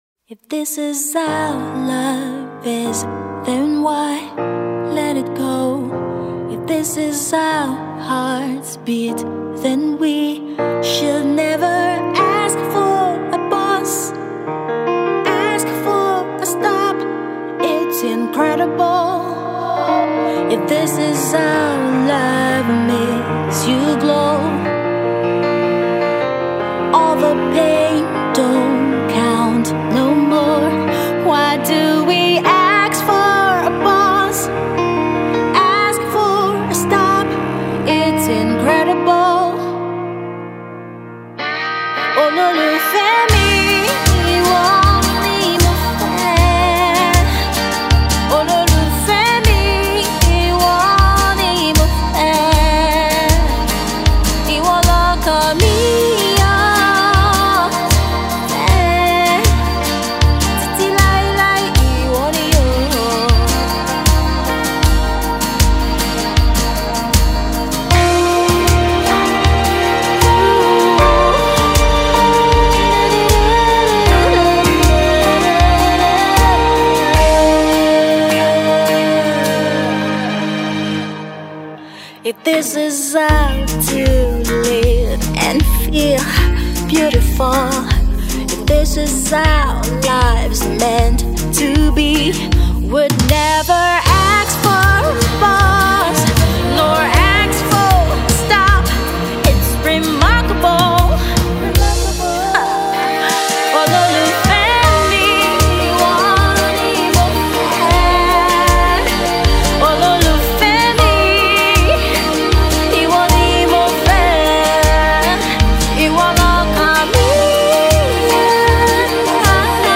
The song alternative rock song is from her forthcoming EP